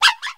pawmi_ambient.ogg